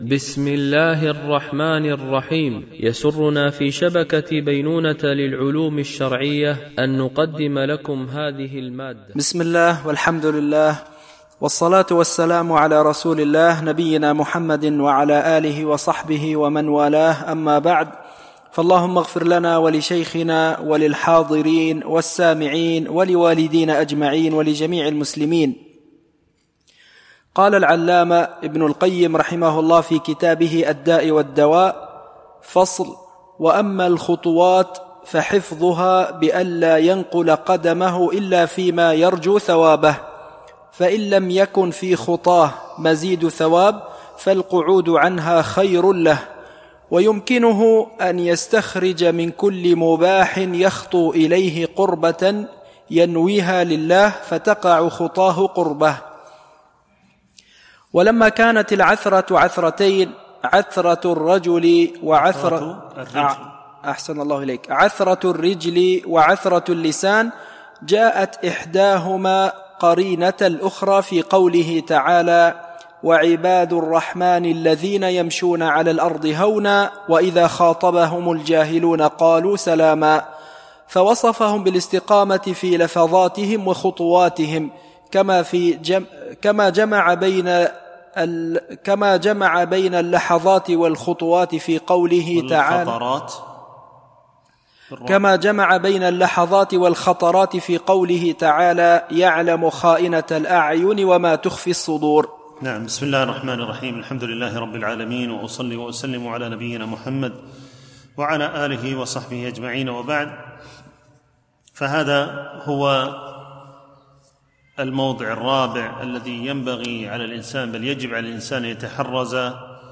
شرح كتاب الداء والدواء ـ الدرس 41